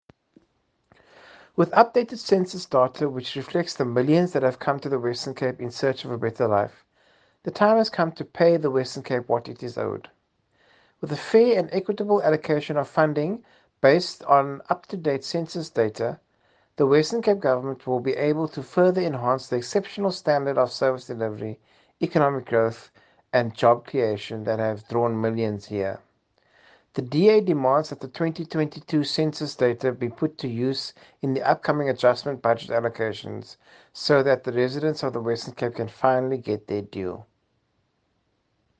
audio by MPP Christopher Fry attached.